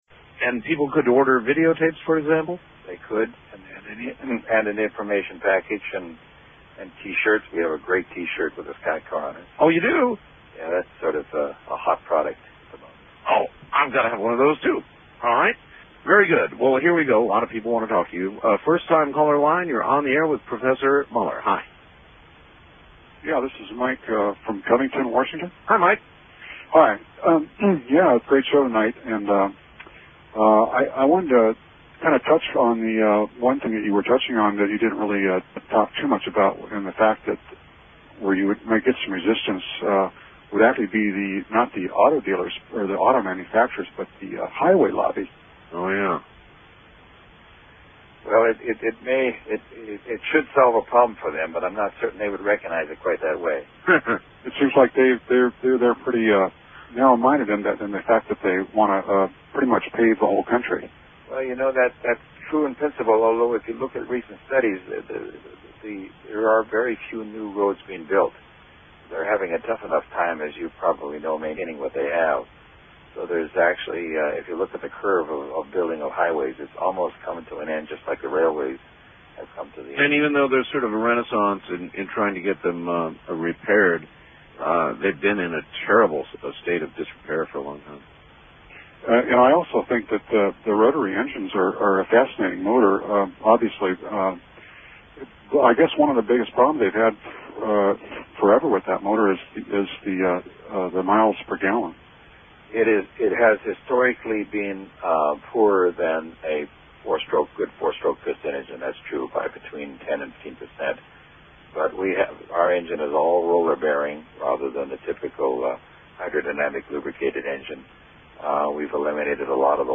Interview 7